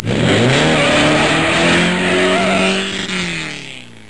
Play, download and share peel out original sound button!!!!
carpeelsout.mp3